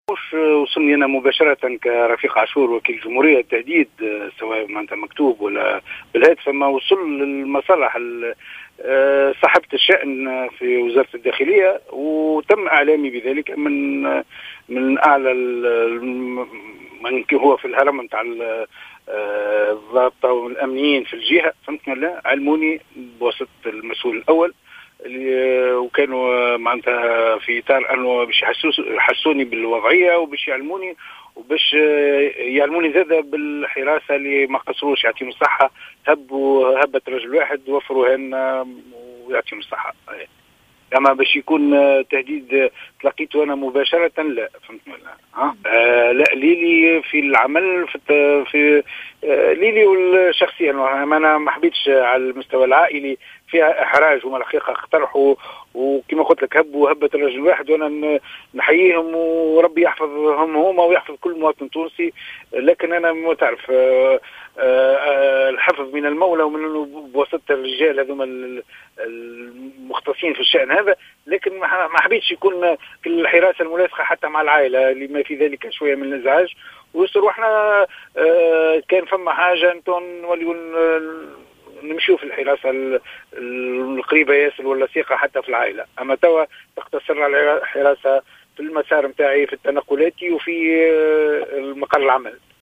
أكد وكيل الجمهورية بالمحكمة الابتدائية بالقيروان،رفيق عاشور في تصريح ل"الجوهرة أف أم" تلقيه تهديدات بالقتل.